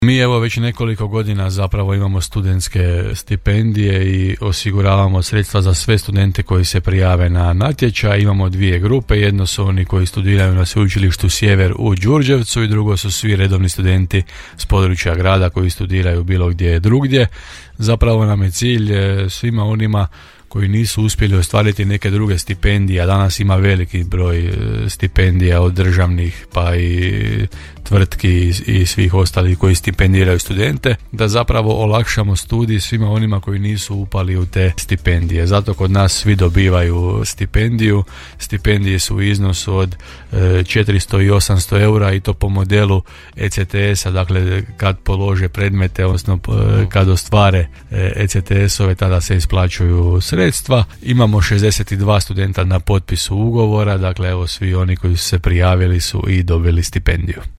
U Hostelu Borik u Đurđevcu 60-tak đurđevačkih studenata s gradonačelnikom Hrvojem Jančijem potpisalo je ugovore o stipendiranju.